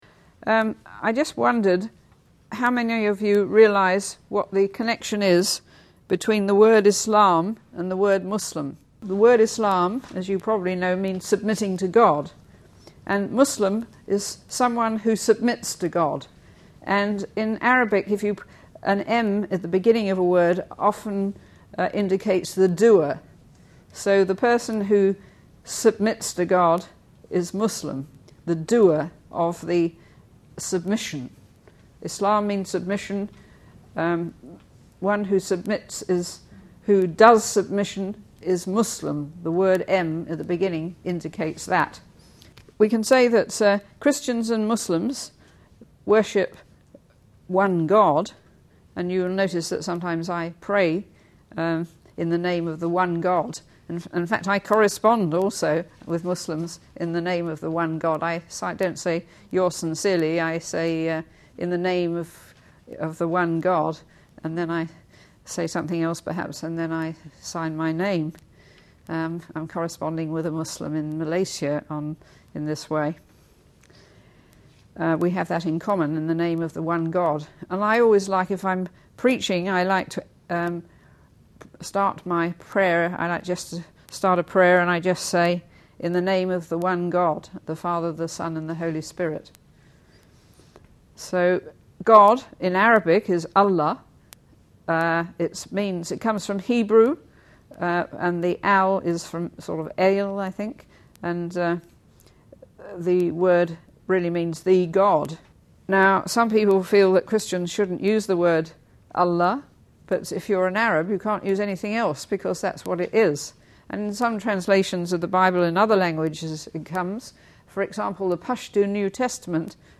These lectures were given at Columbia International University in partnership with the Zwemer Center for Muslim Studies. The Zwemer Center was founded in 1979 and exists to offer comprehensive courses on Islam, facilitate research, foster dialogues, offer seminars, conduct training, and provide resources for effective witness and ministry among Muslims.